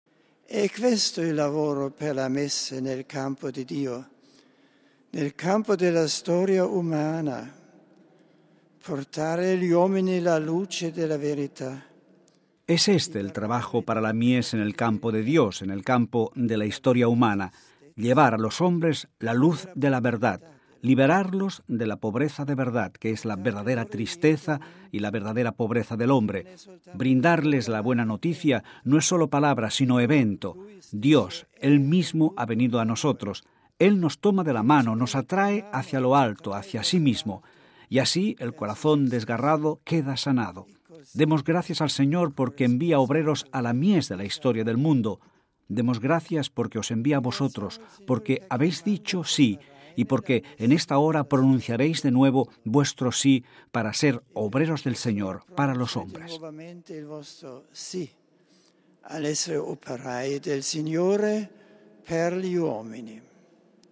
El Santo Padre ha comenzado su homilía dirigiéndose con afecto a cada uno de ellos: Mons. Savio Hong Tai-Fai, secretario de la Congregación para la Evangelización de los Pueblos; Mons. Marcello Bartolucci, secretario de la Congregación para las Causas de los Santos; Mons. Celso Morga Iruzubieta, secretario de la Congregación para el Clero; Monseñor Antonio Guido Filipazzi, nuncio apostólico, al igual que Mons. Edgar Peña Parra.